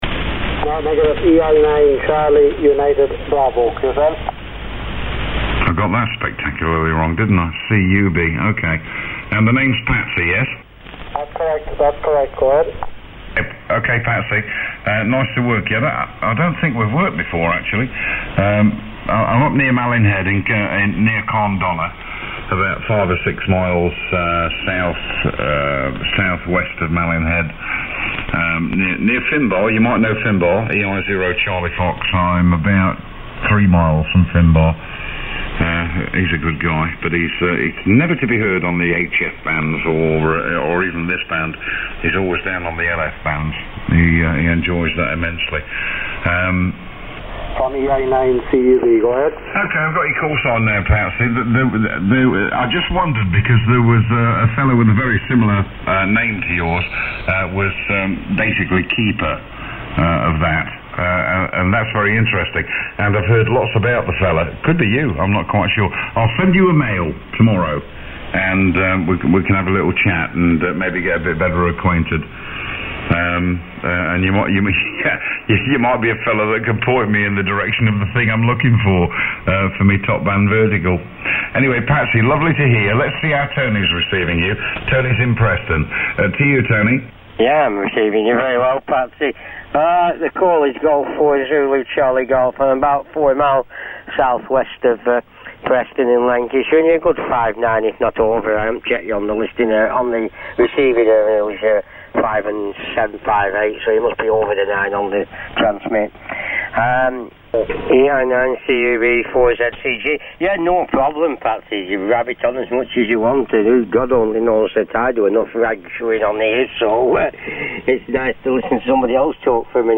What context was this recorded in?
Below are links to some sample audio clips taken from the PERSEUS receiver of audio quality on a strong local AM station, strong SSB signals, and side by side with other receivers. PERSEUS SSB sample. 23rd January 2008, 2338UTC, 1933kHz Top Band QSOs, LSB (1314k)